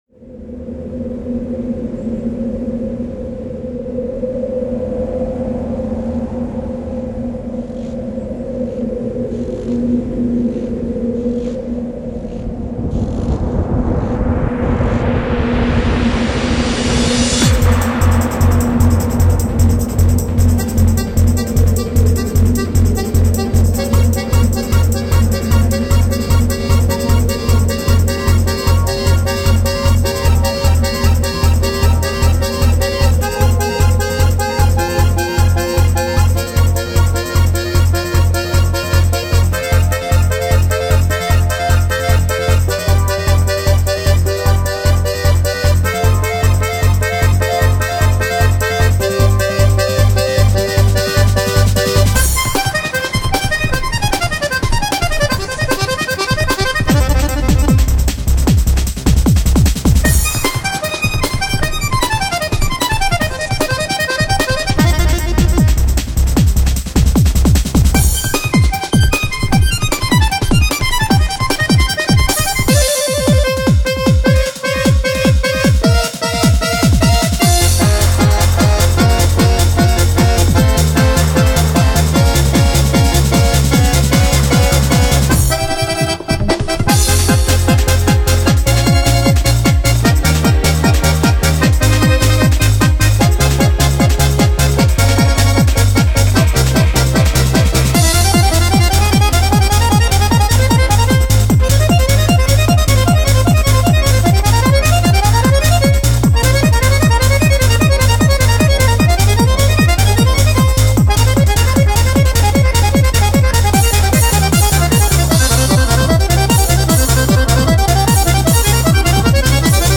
свой неповторимый стиль виртуозной игры на двух баянах